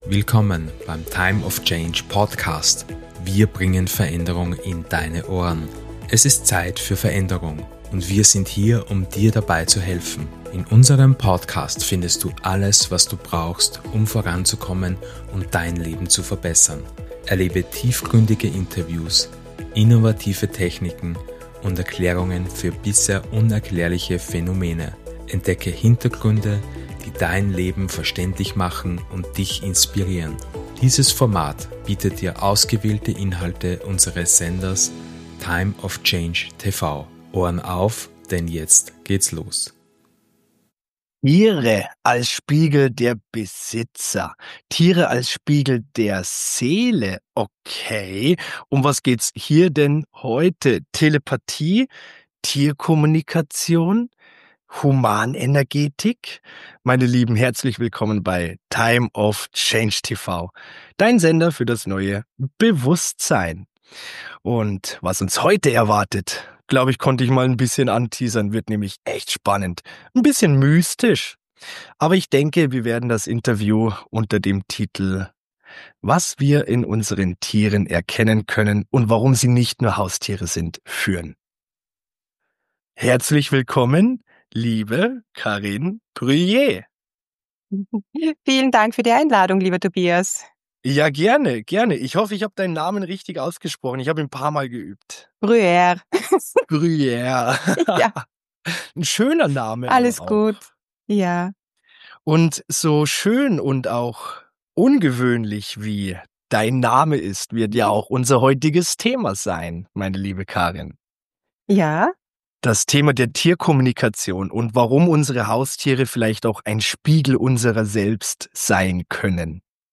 Dieses Interview inspiriert, gibt tiefe Einblicke und zeigt praxisnah, wie echter Wandel möglich ist, im Innen wie im Außen.